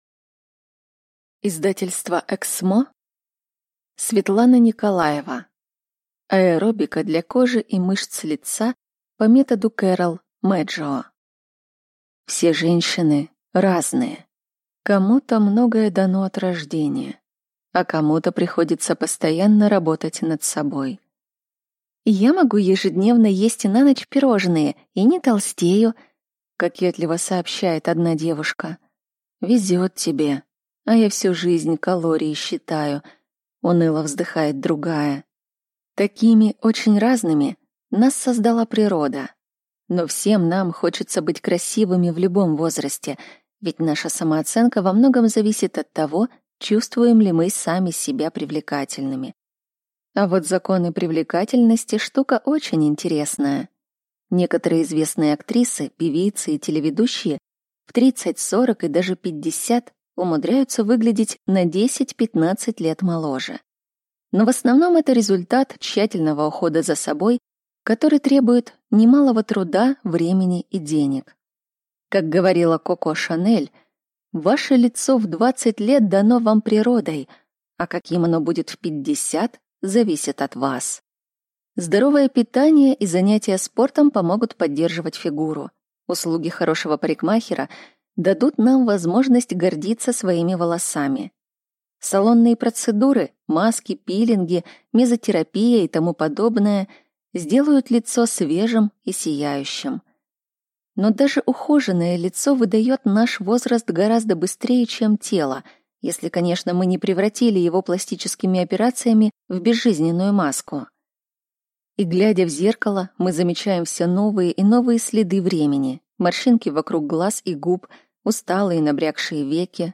Аудиокнига Аэробика для кожи и мыщц лица по методу Кэрол Мэджио | Библиотека аудиокниг